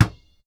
basketball-3.wav